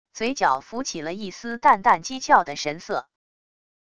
嘴角浮起了一丝淡淡讥诮的神色wav音频生成系统WAV Audio Player